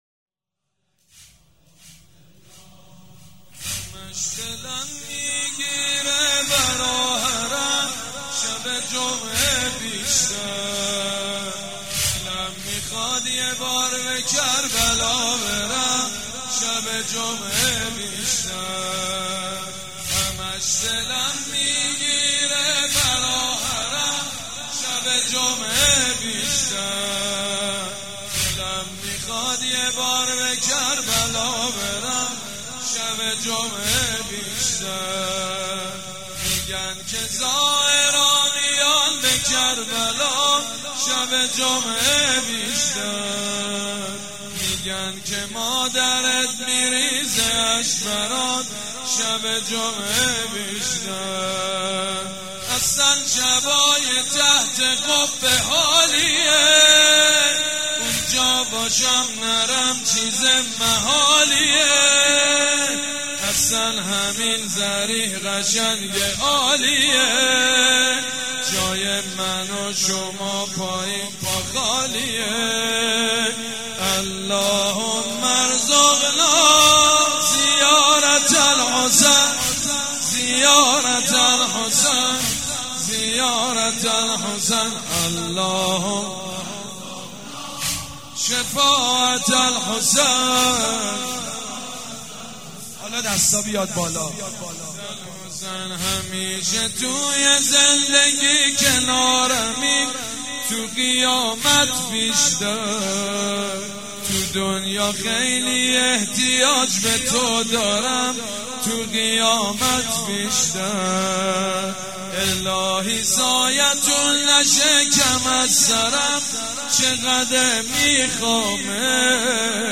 شب سوم فاطميه دوم١٣٩٤
مداح
حاج سید مجید بنی فاطمه
مراسم عزاداری شب سوم